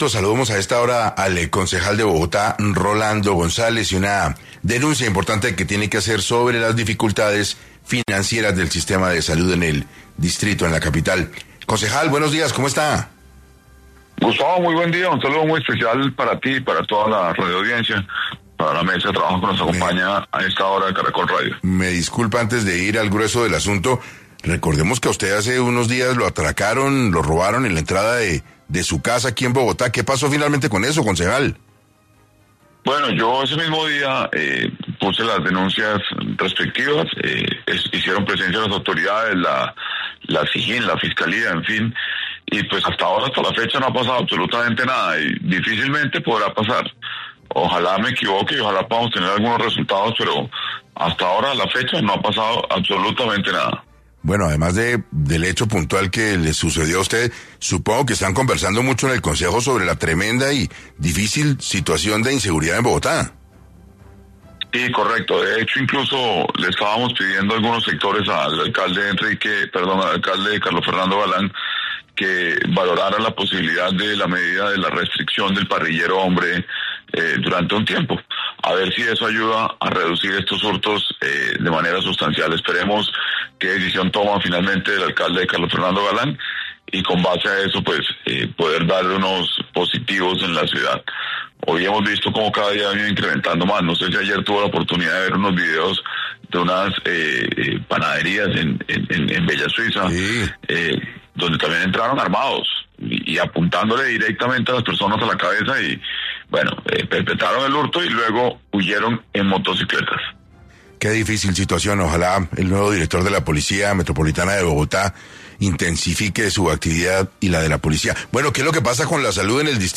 El concejal Rolando González reveló en 6AM Hoy x Hoy de Caracol radio que, por falta de recursos, el sistema de salud de la ciudad puede entrar en un colapso total en la prestación de servicios.